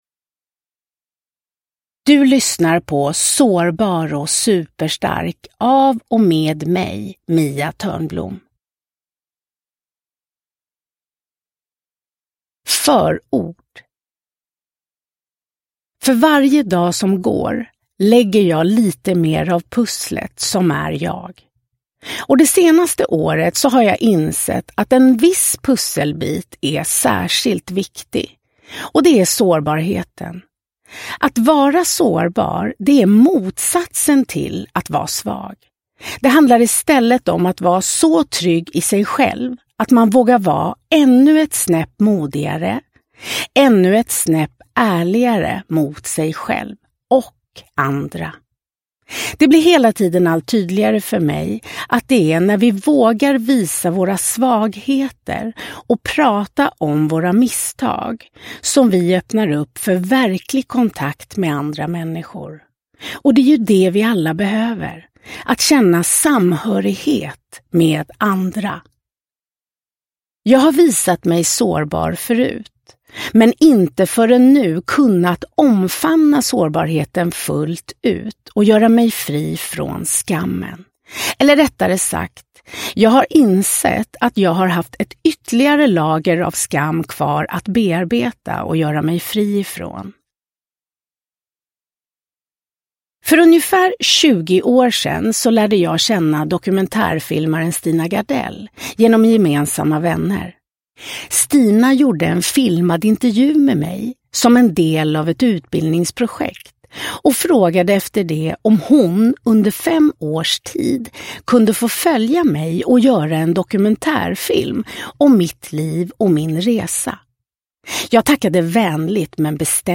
Uppläsare: Mia Törnblom
Ljudbok